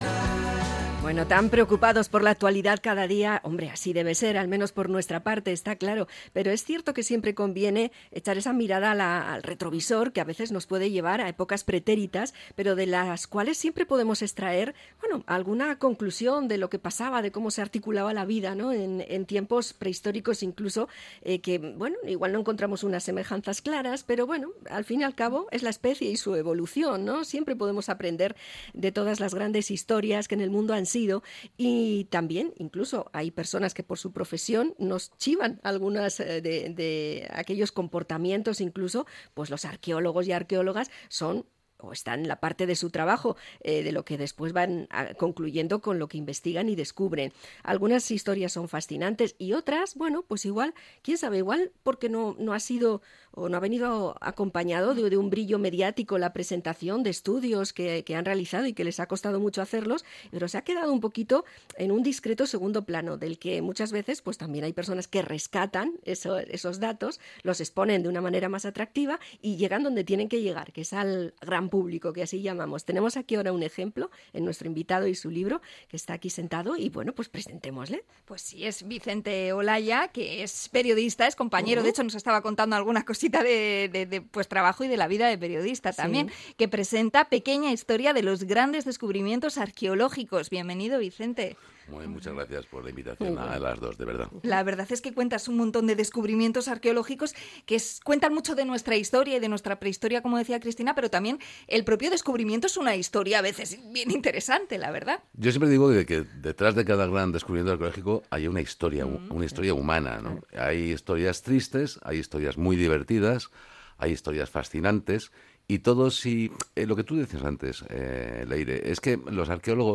INT.-GRANDES-DESCUBRIMIENTOS-ARQUEOLOGICOS.mp3